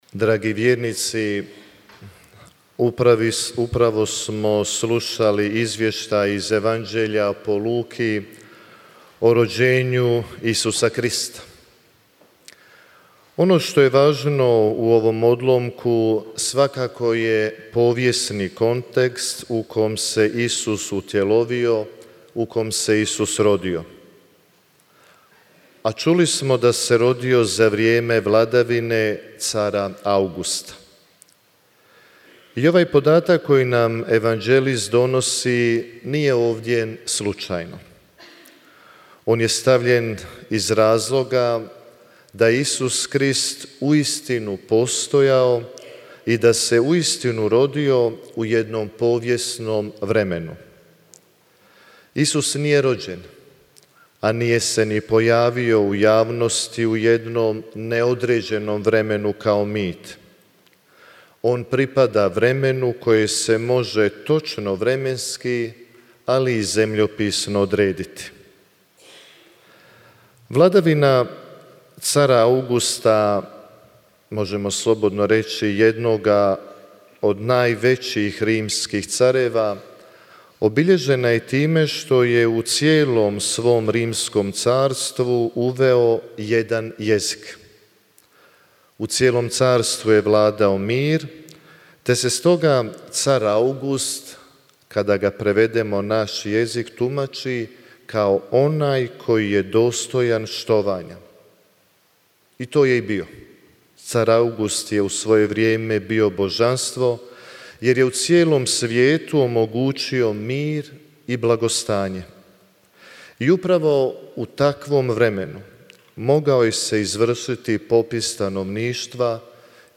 FOTO: Polnoćka u Međugorju